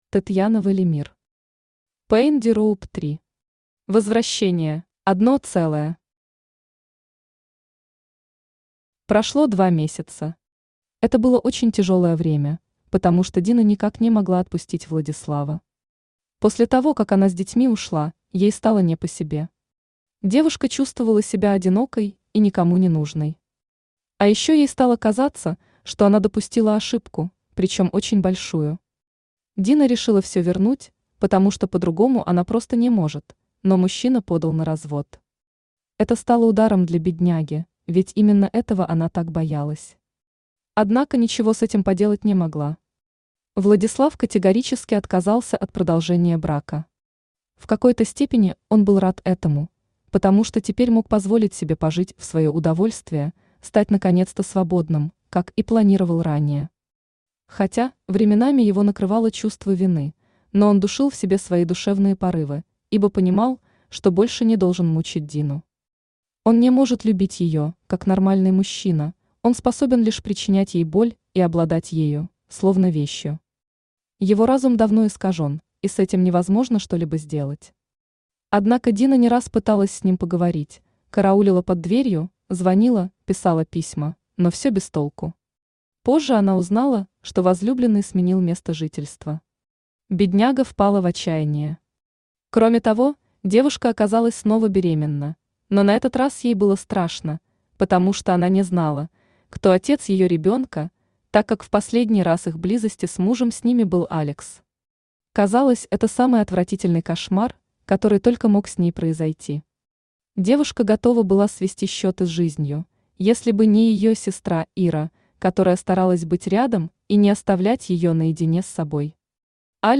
Возвращение Автор Tatyana Velimir Читает аудиокнигу Авточтец ЛитРес.